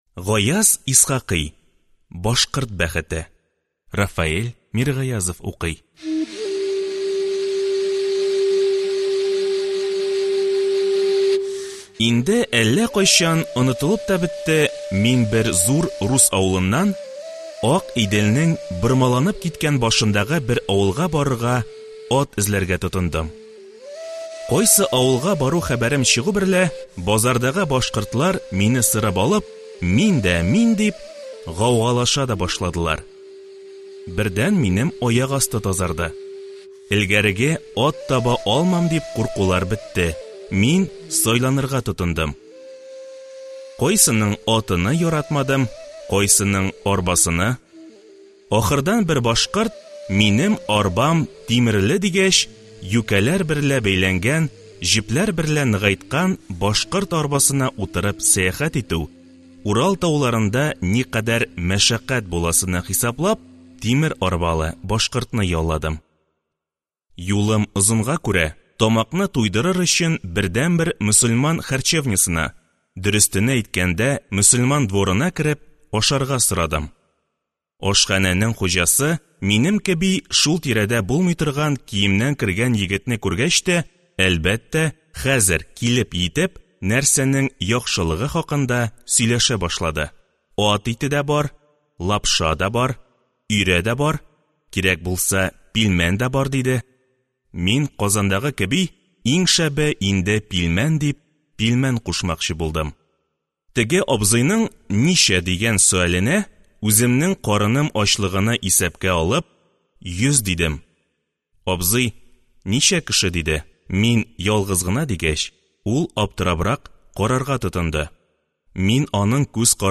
Аудиокнига Башкорт бәхете | Библиотека аудиокниг